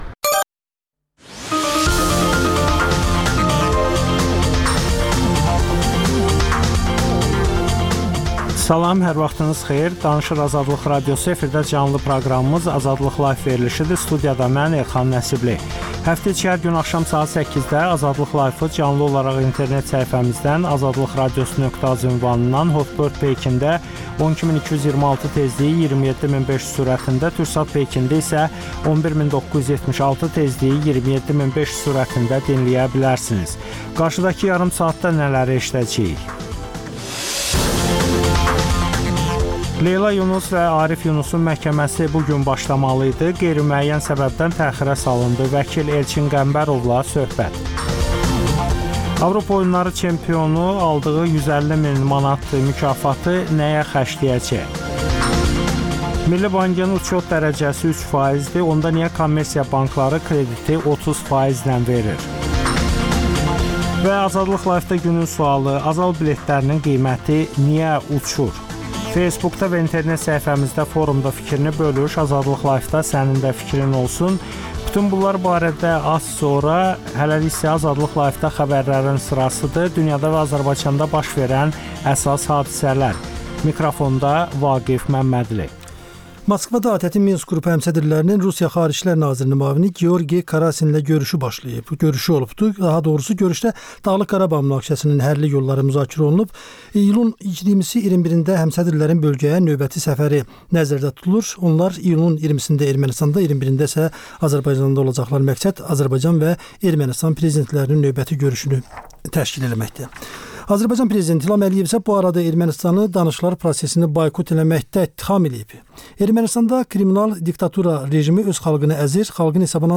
Azərbaycanda və dünyda baş verən hadisələrin ətraflı analizi, təhlillər, müsahibələr.